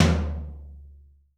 TOM 3L.wav